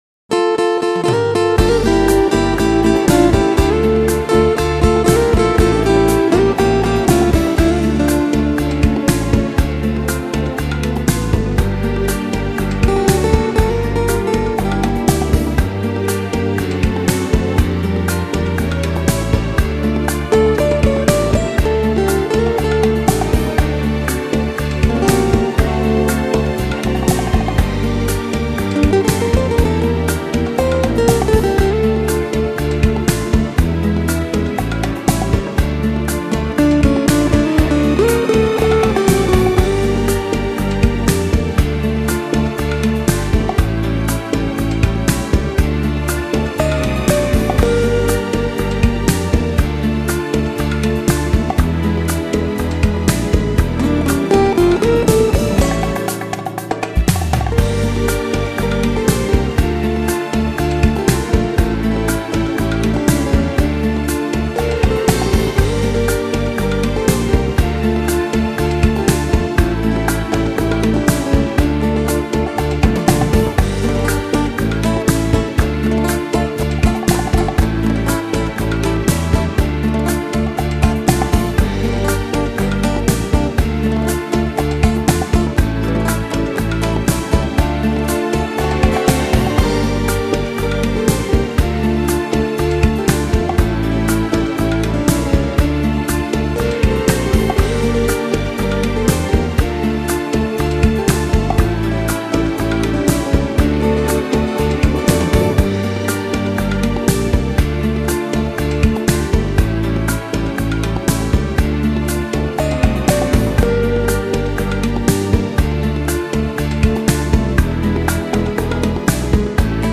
Genere: Bachata
Scarica la Base Mp3 (3,36 MB)